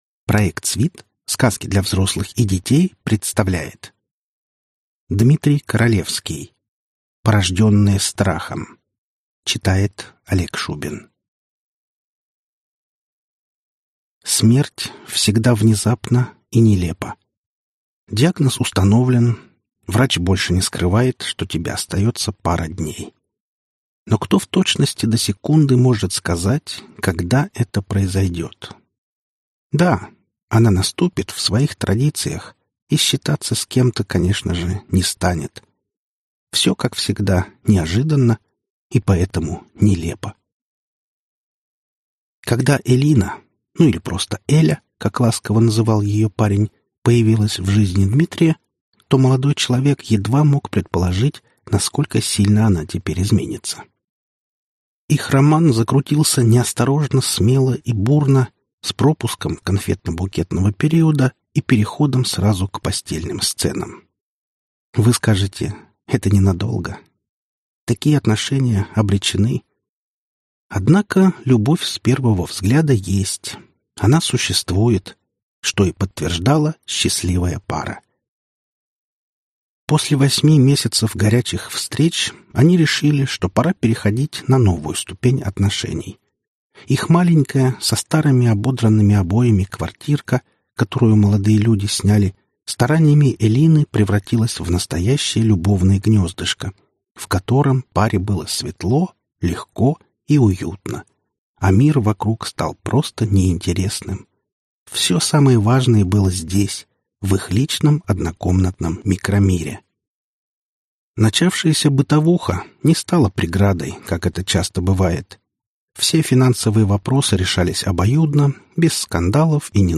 Аудиокнига Страшная реальность | Библиотека аудиокниг